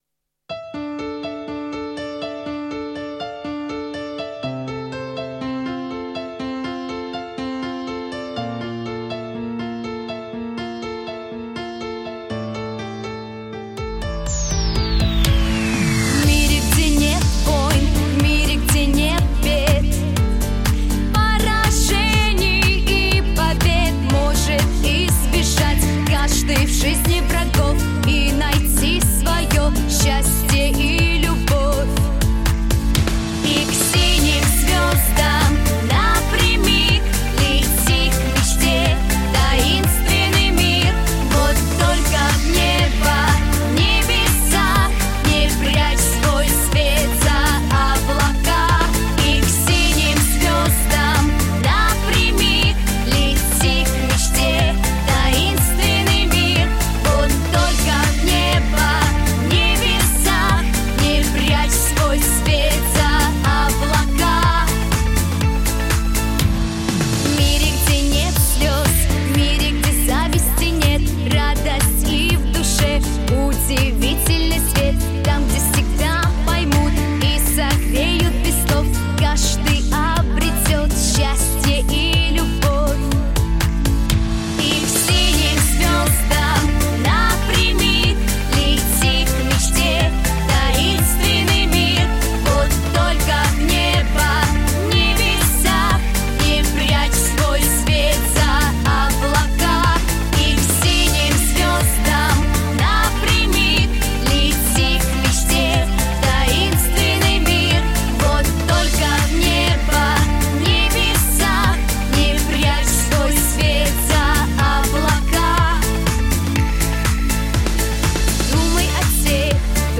Аудиокнига Навстречу звездам | Библиотека аудиокниг
Читает аудиокнигу Вокальная группа «Дар»